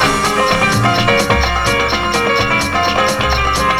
SALSA LOO0BR.wav